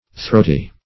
Throaty \Throat"y\, a.